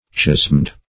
Meaning of chasmed. chasmed synonyms, pronunciation, spelling and more from Free Dictionary.
Chasmed \Chasmed\ (k[a^]z'md)